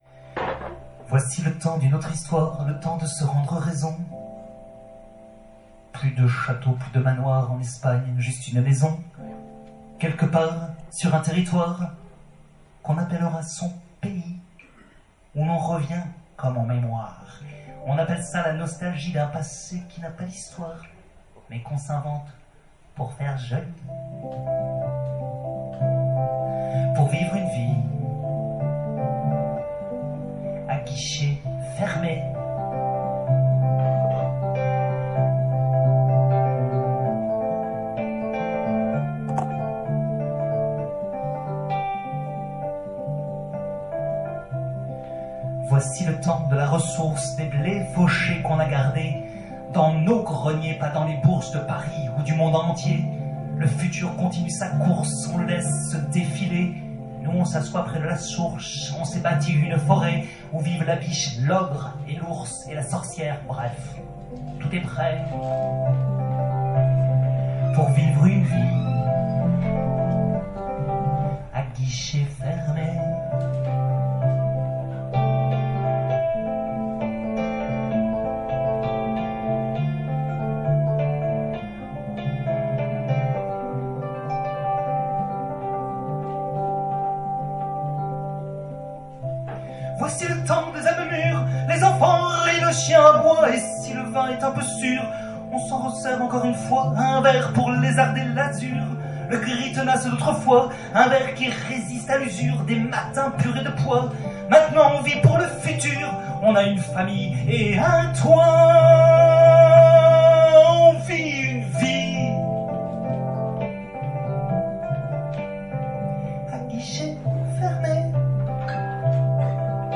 Chez Adel, 3 novembre 2016